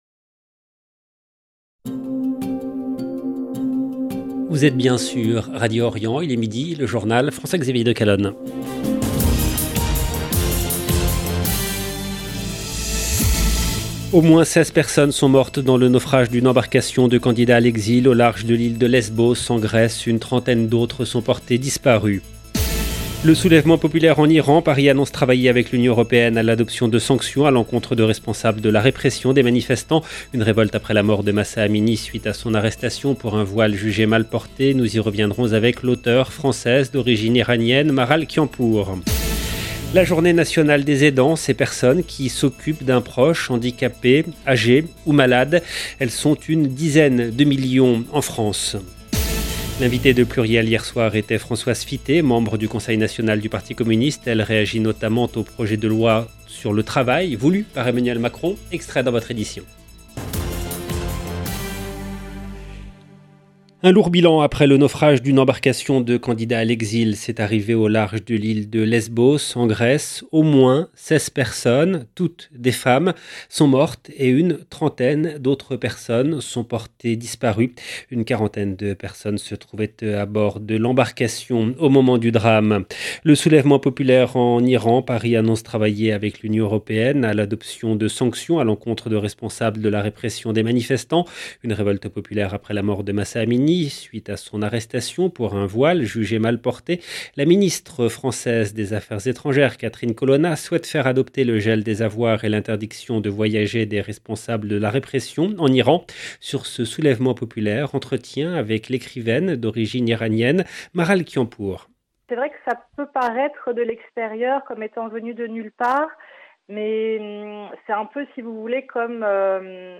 LE JOURNAL EN LANGUE FRANCAISE DE MIDI DU 6/10/22